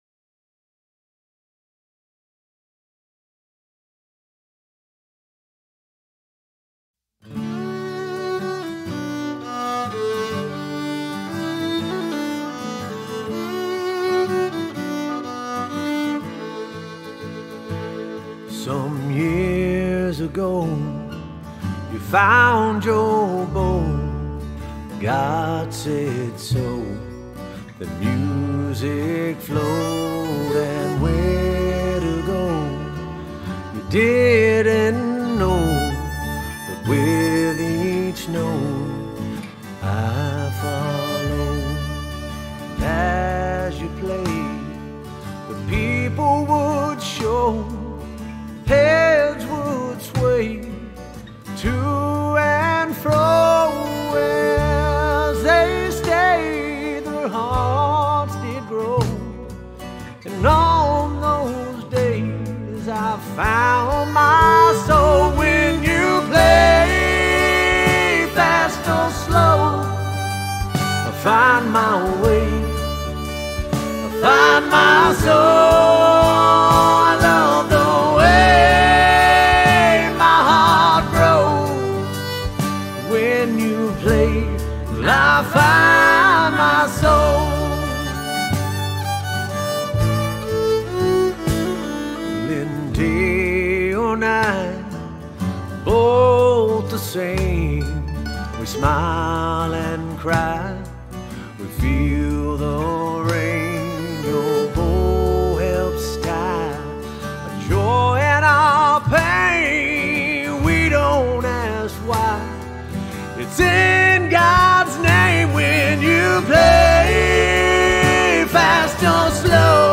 Vocals/Guitar
Violin
Guitar
Backup Vocals
Mandolin
Drums
Upright Bass